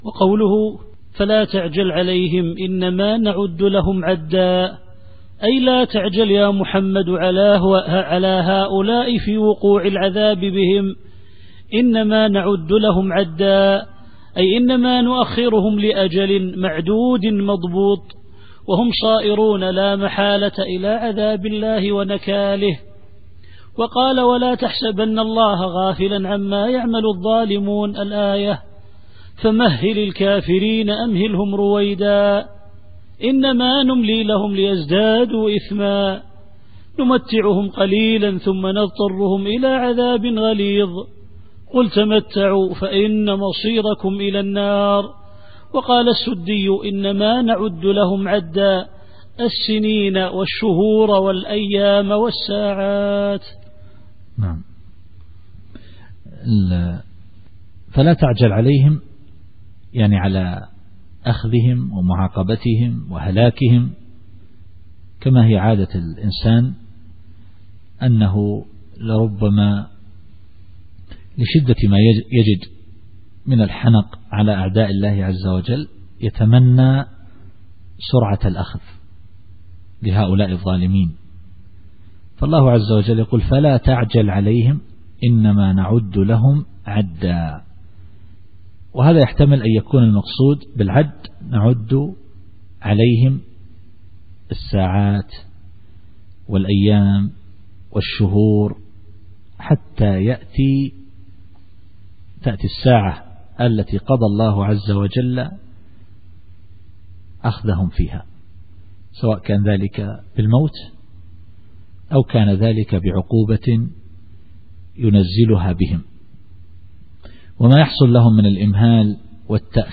التفسير الصوتي [مريم / 84]